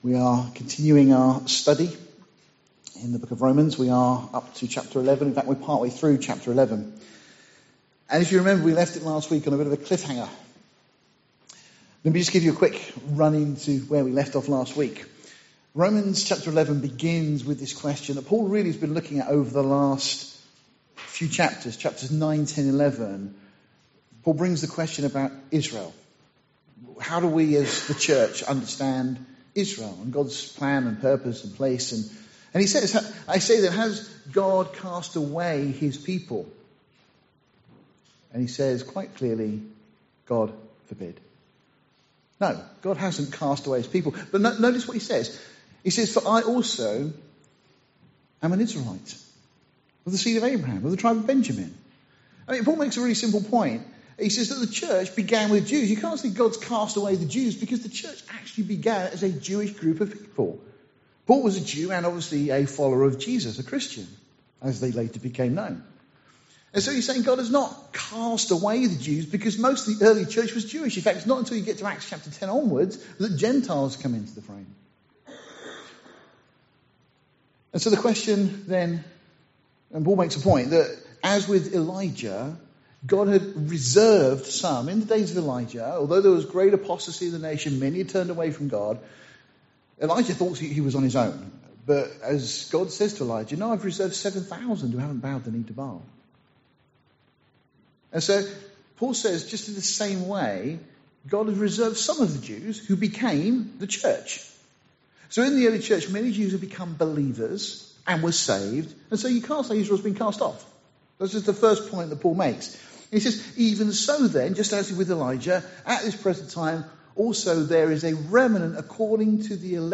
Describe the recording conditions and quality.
Series: Sunday morning studies Tagged with Israel , The Tribulation , verse by verse